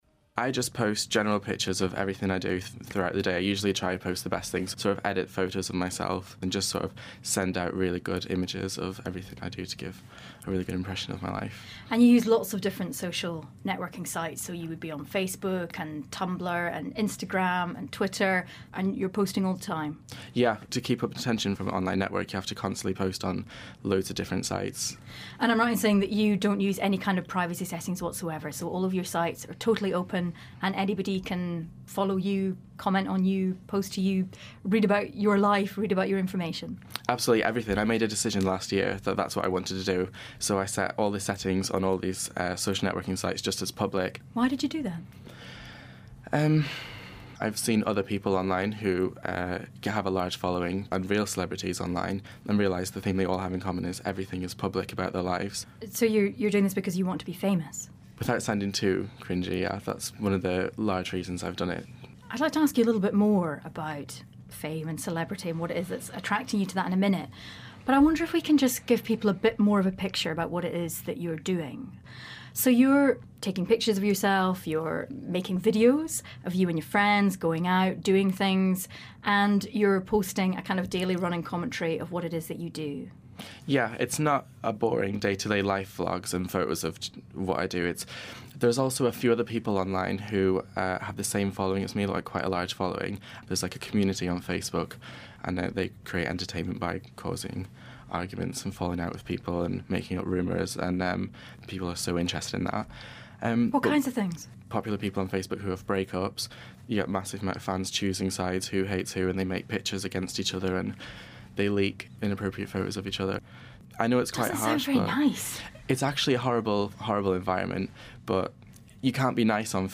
One young man describes what happened when Facebook made a small change to their profile pages and how it's changed his life. He describes 'haters', 'meetups' and why he thinks fame for its own sake is worth pursing.